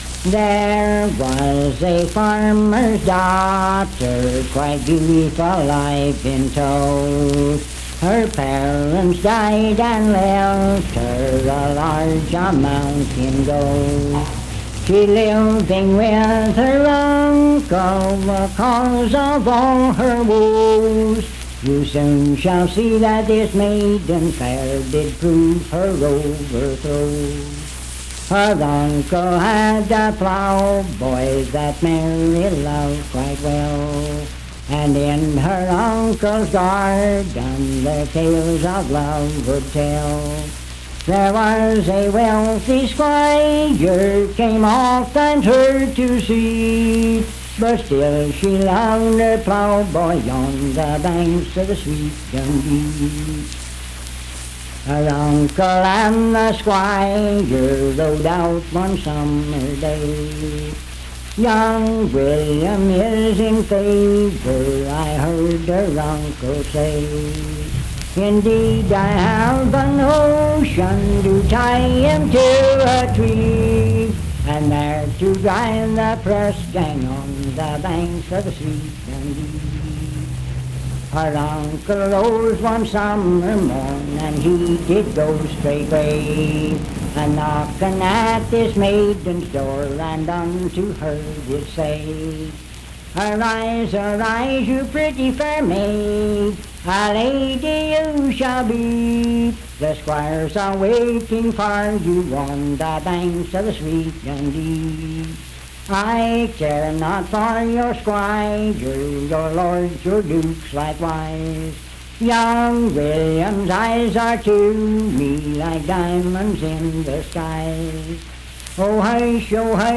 Unaccompanied vocal music
Verse-refrain 10d(4w/R).
Performed in Sandyville, Jackson County, WV.
Voice (sung)